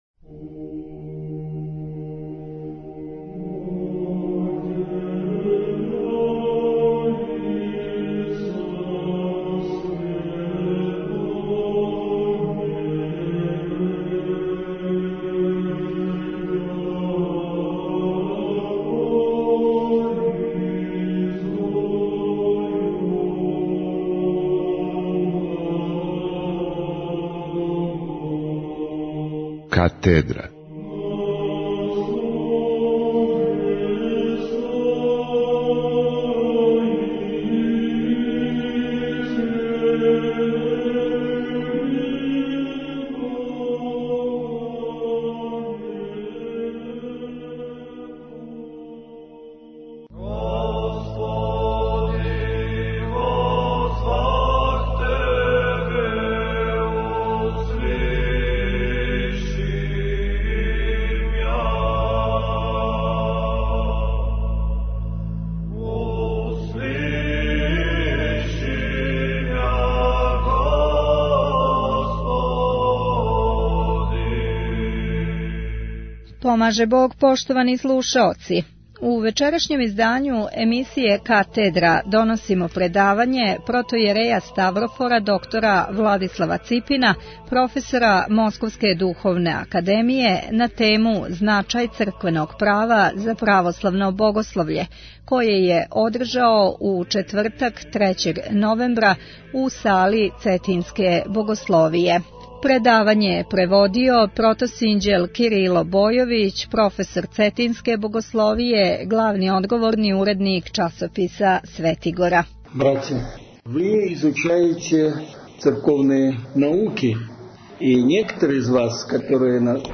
у Цетињској Богословији предавање